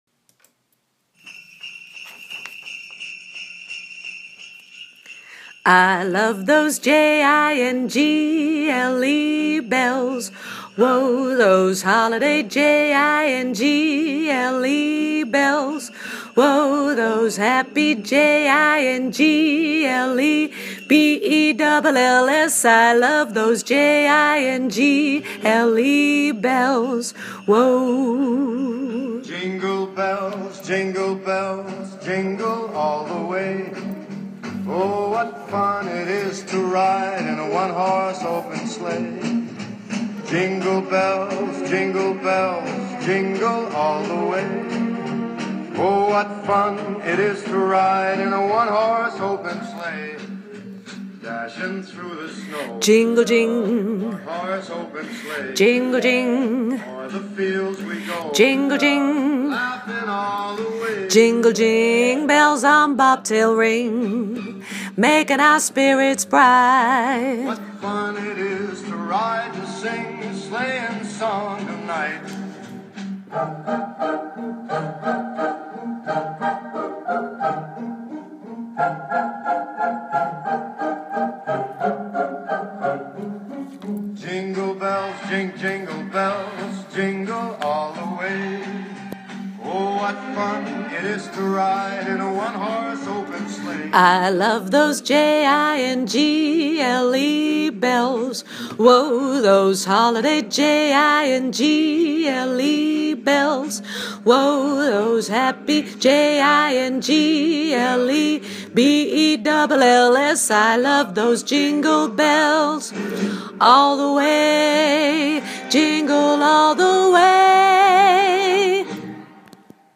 koortje tenor/bas